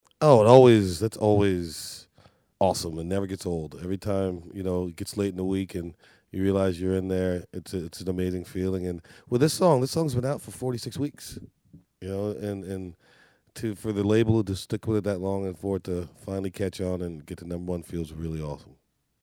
Audio / Darius Rucker talks about "If I Told You" hitting the top of the country charts.